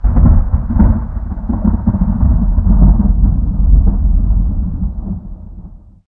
thunder23.ogg